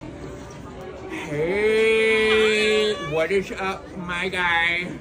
Heyyyyyyyyyy - Botão de Efeito Sonoro